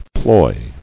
[ploy]